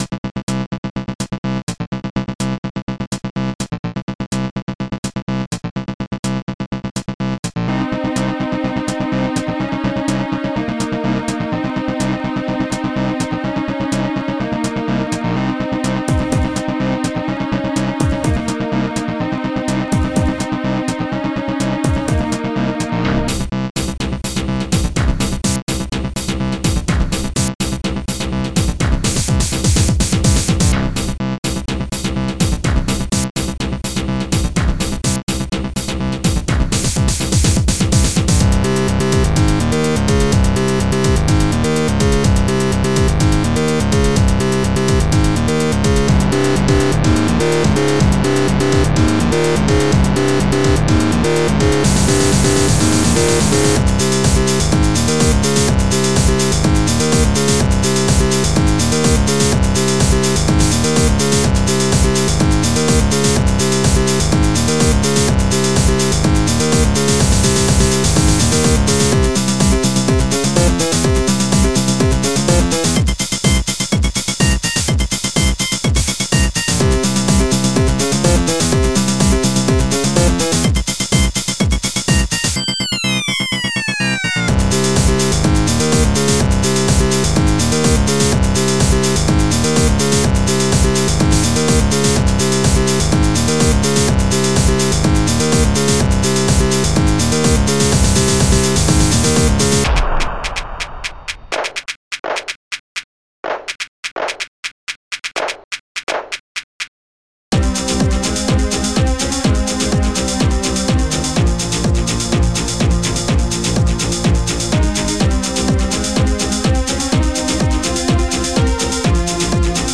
Protracker and family
snare
acid-claps
Strings7
bassdrum1
bass7
hihat2